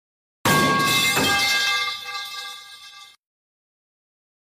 На этой странице собраны разнообразные звуки железной палки: от резких звонких ударов до протяжных вибраций.
Железный прут упал